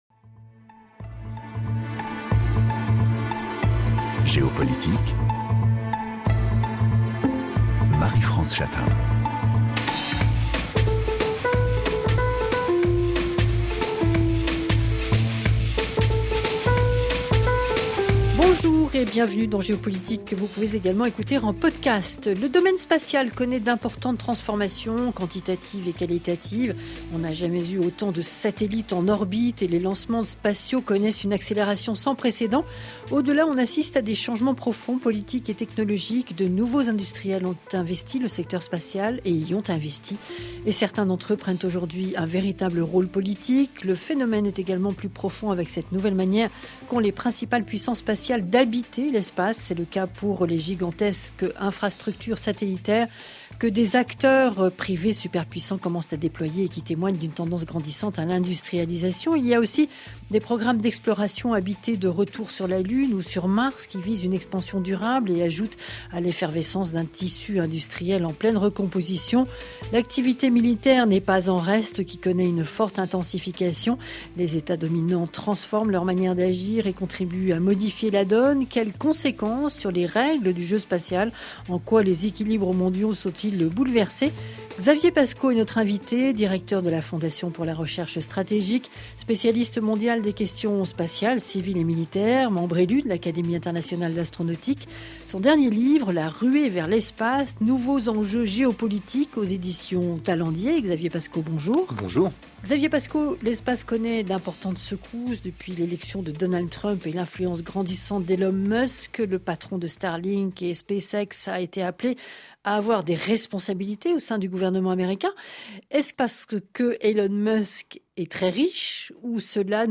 Les invités de Géopolitique confrontent leurs regards sur un sujet d’actualité internationale.